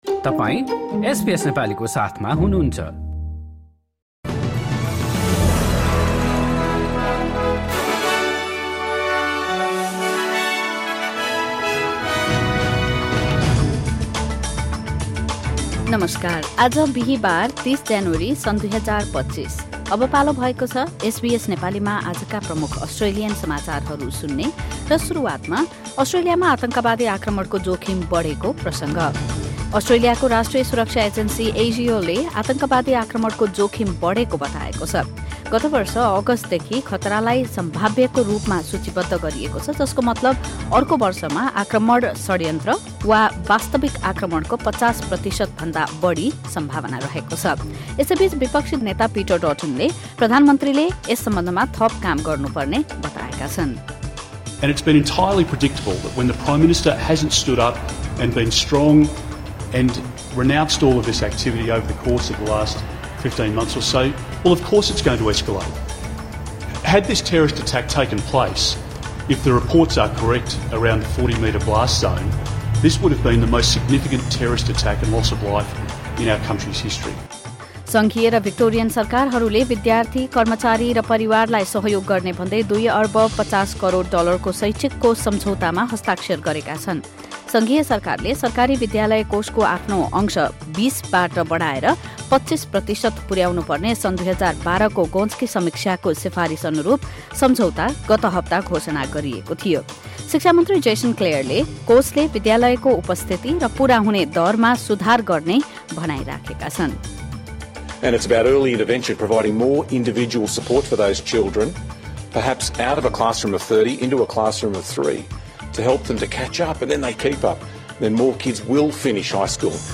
SBS Nepali Australian News Headlines: Thursday, 30 January 2025